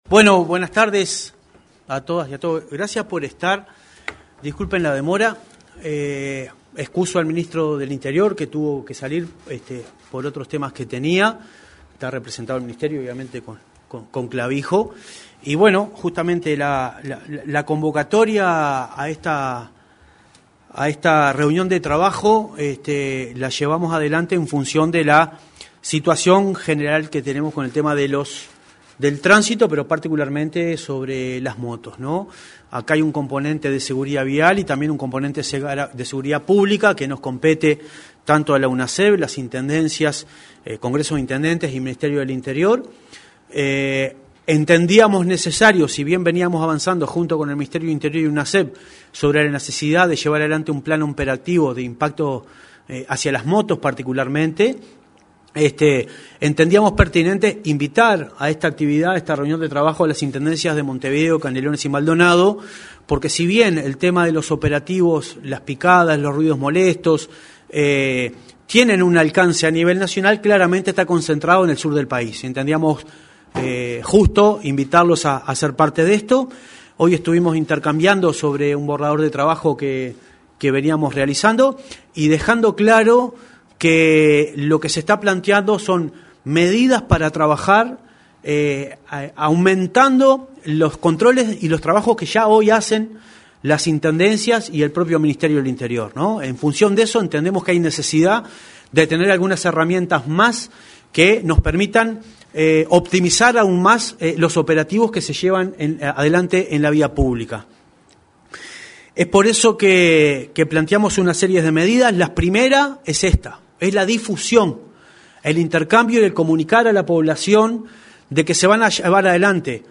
Conferencia de prensa de Unasev
En la ocasión, se expresaron el presidente de la Unasev, Marcelo Metediera; el subdirector de la Policía Nacional, Alfredo Clavijo, y el intendente de Montevideo, Mauricio Zunino.